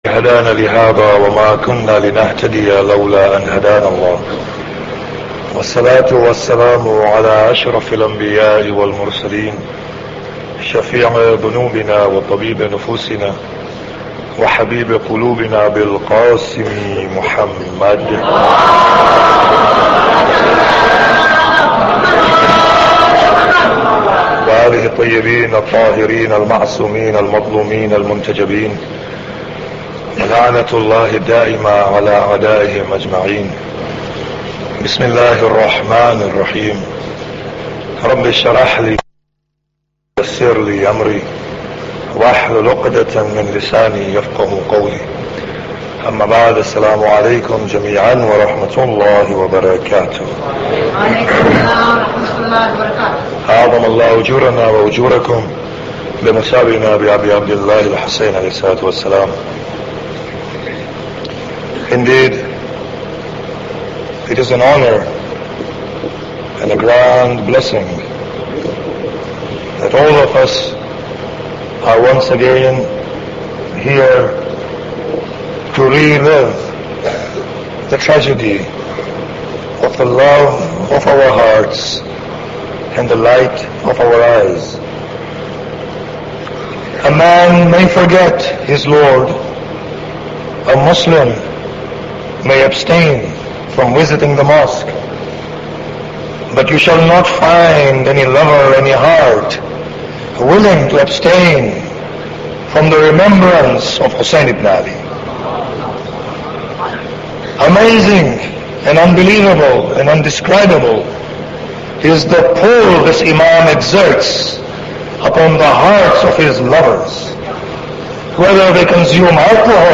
Muharram Lecture 1